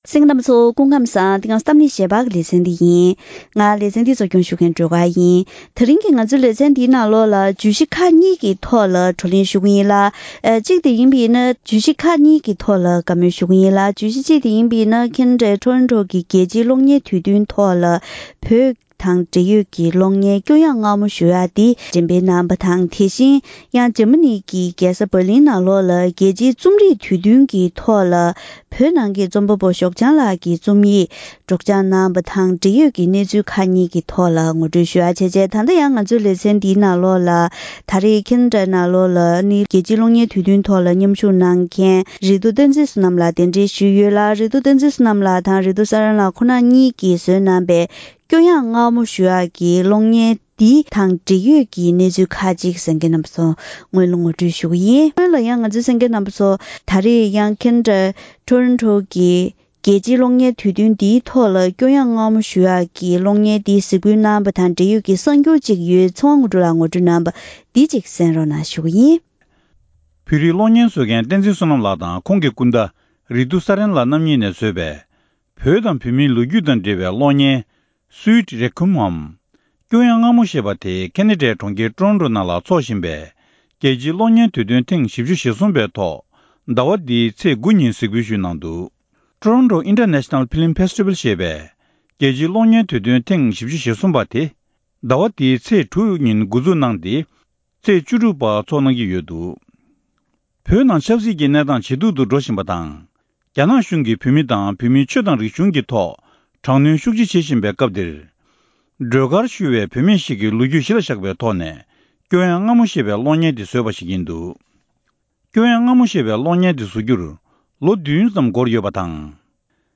གཏམ་གླེང་ཞལ་པར